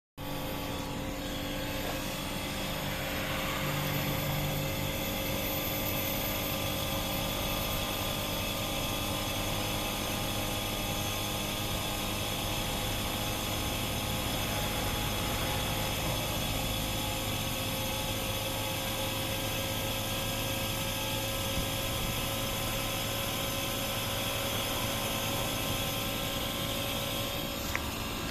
Je constate toujours comme un bruit de sifflement dans l'UE, et dans l' UI la plus grosse (7.1kW) on entend ce sifflement et elle fait légèrement "glou glou" comme si on entendait le liquide qui passait dans la liaison (en mode chaud en tout cas).
Si on écoute bien, derrière le bruit de mobylette (compresseur ?) on entend le sifflement dont je parle.